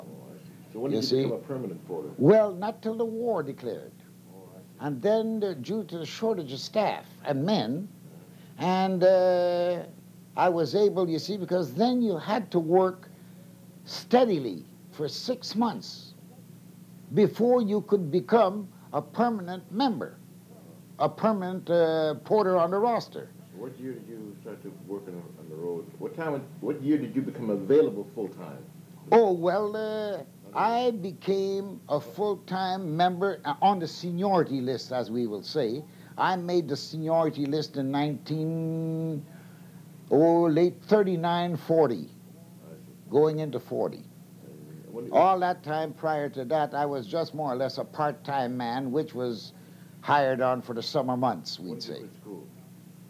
En les écoutant attentivement, nous arrivons à construire un récit et à replacer les images du MDN en contexte.